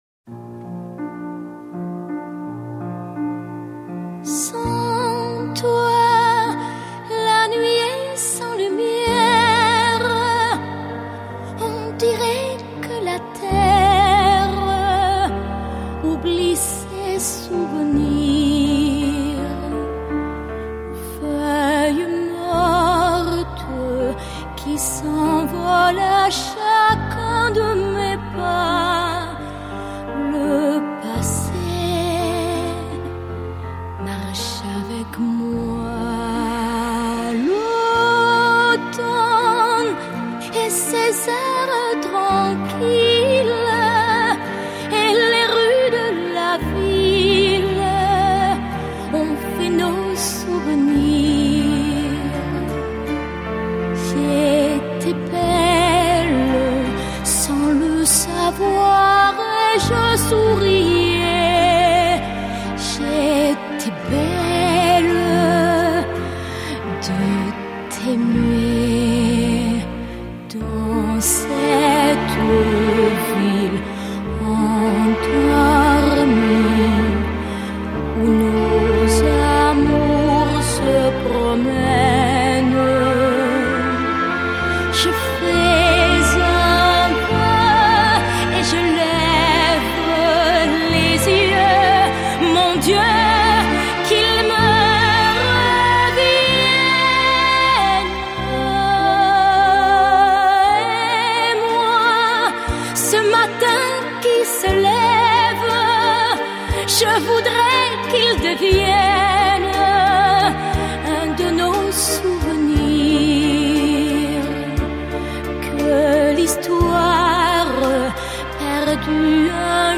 法国香颂